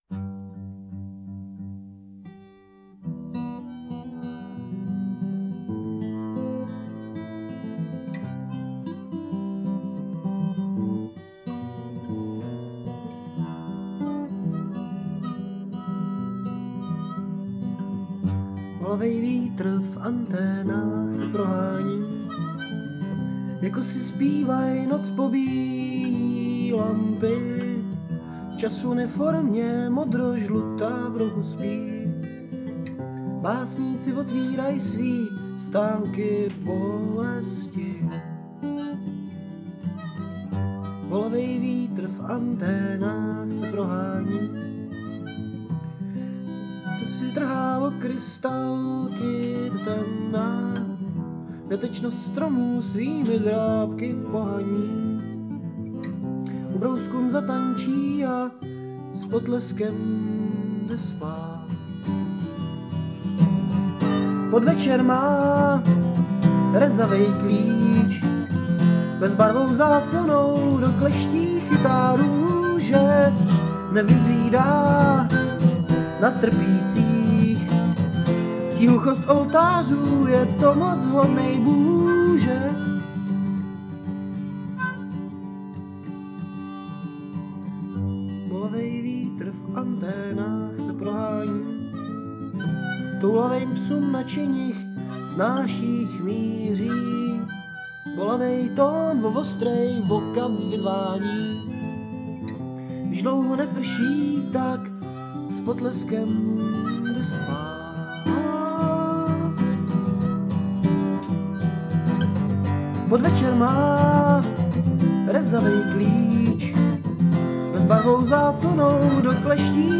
kbd, harm, dr
g, sitar, harm